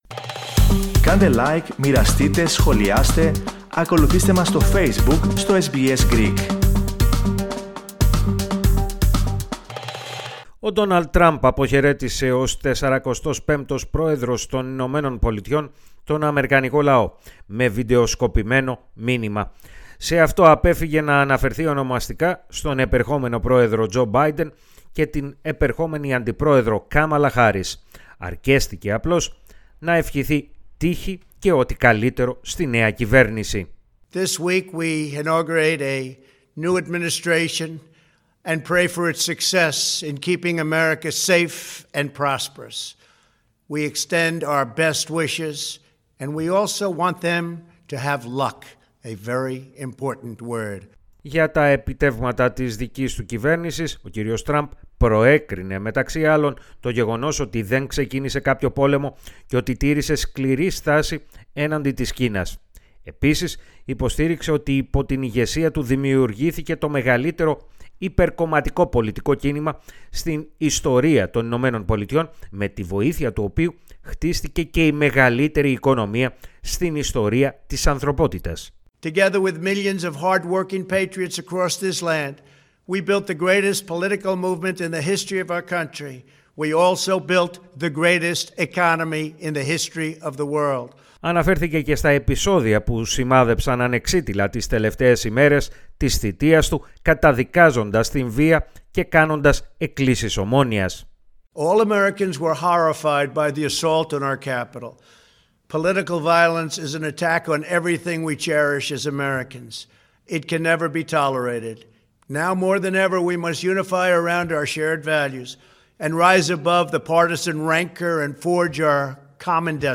αναφορά